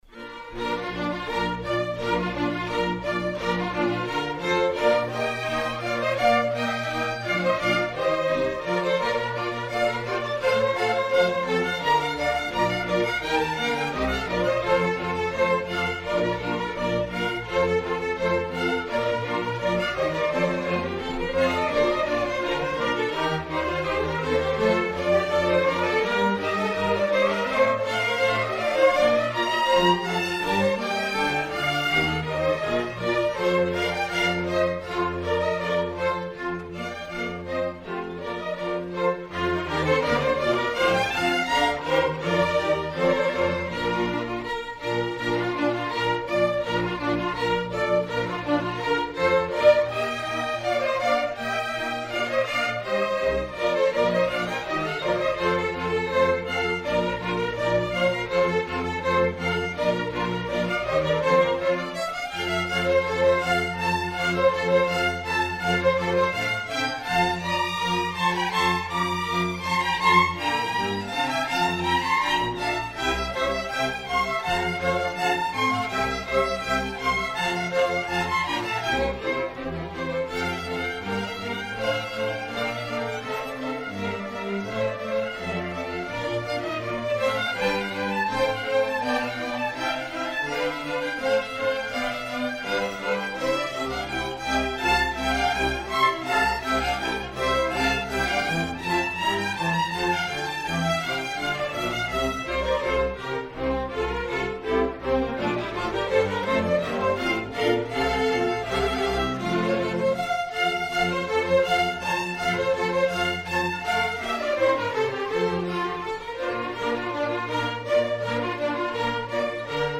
Queen City Community Orchestra
Fall 2018 Concert